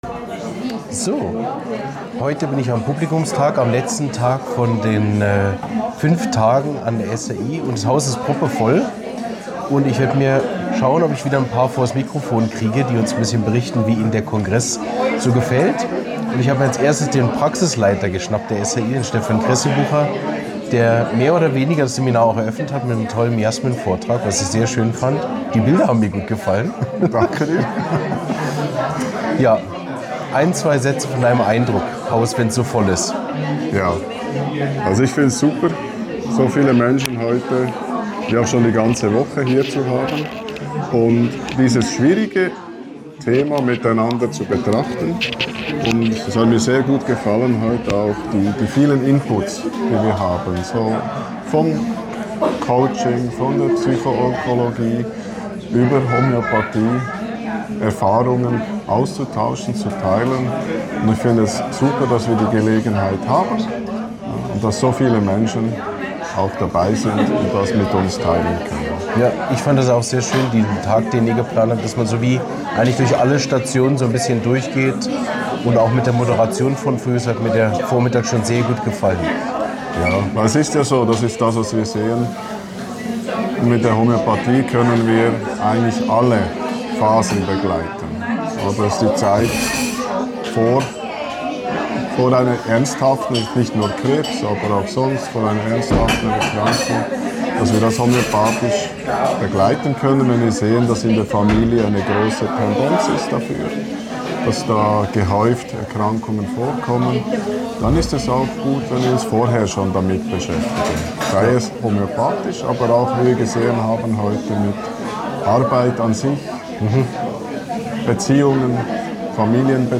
Spezialepisode Stimmen zum Publikumstag